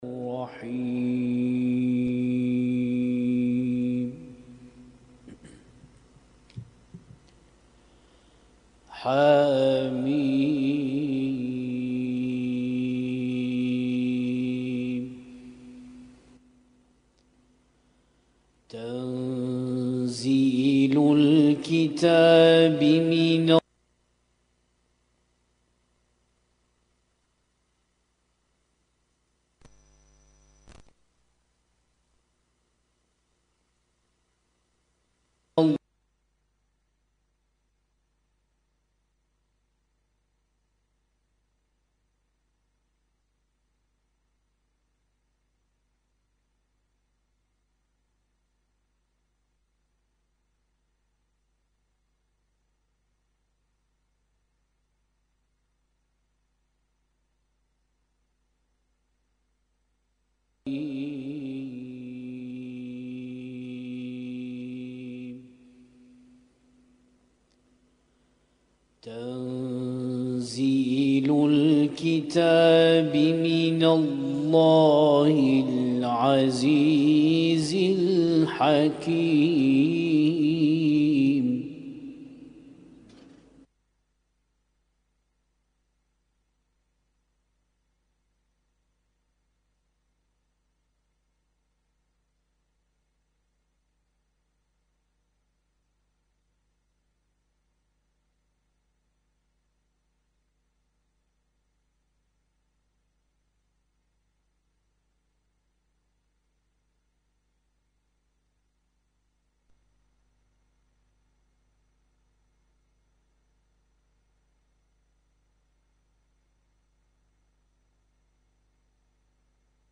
اسم التصنيف: المـكتبة الصــوتيه >> القرآن الكريم >> القرآن الكريم - القراءات المتنوعة